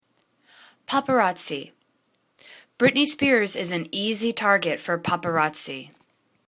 pap.a.raz.zi     /popə'rotsi/    plural